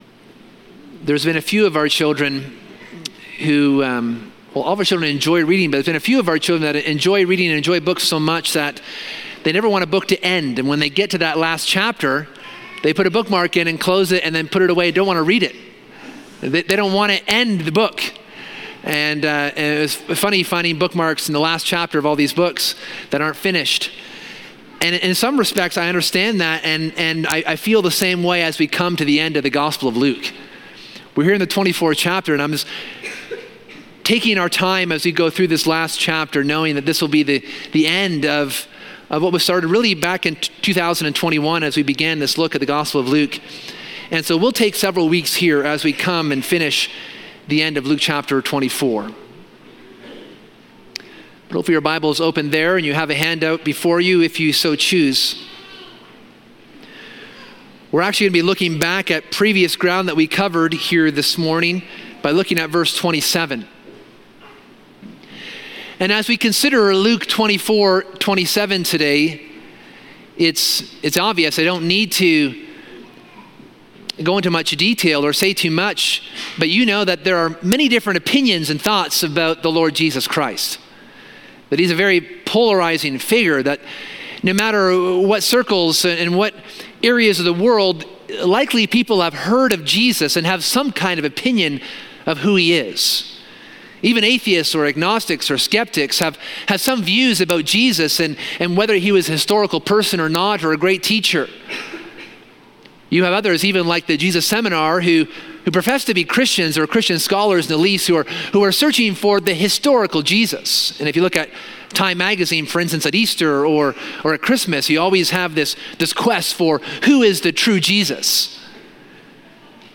This sermon explores how the Old Testament Scriptures prophetically reveal the identity, suffering, and resurrection of Jesus Christ, as taught by Jesus himself to the disciples on the Emmaus road.